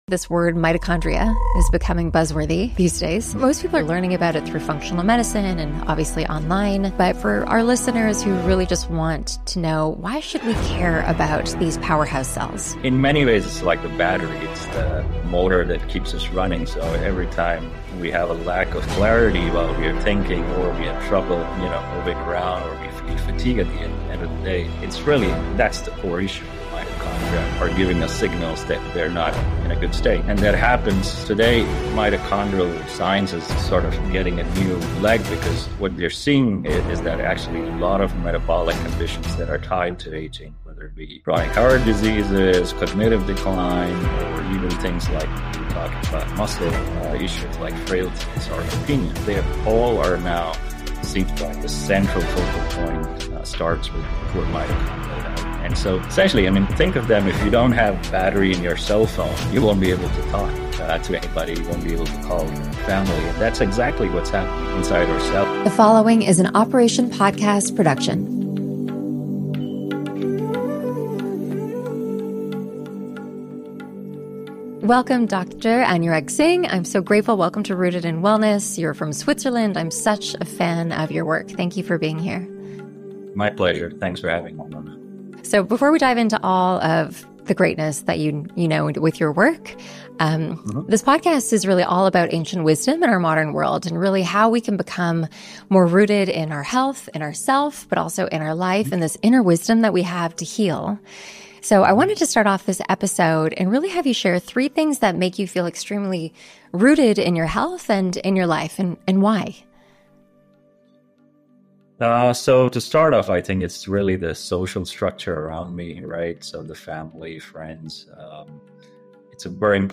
In this conversation, we talk about ancient remedies and their role in addressing chronic conditions, and the central role of mitochondria in aging and longevity.